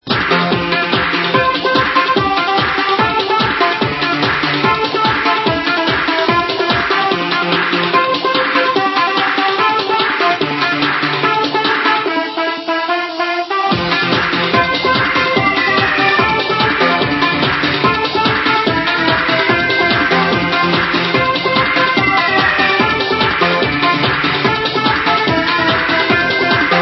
yeah I remember it 2, kinda cheesy song, don't know it tho